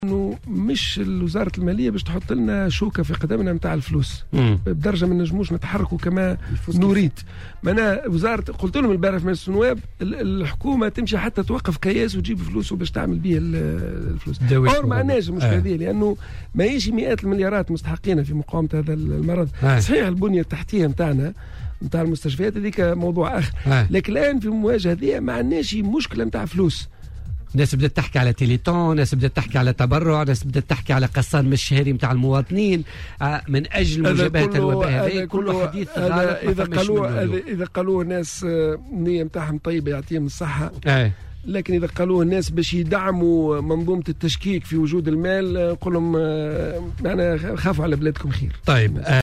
Lors de son intervention dans l'émission Politica de ce jeudi, le ministre a également nié l'épuisement des ressources financières consacrées par l'Etat pour lutter contre le coronavirus.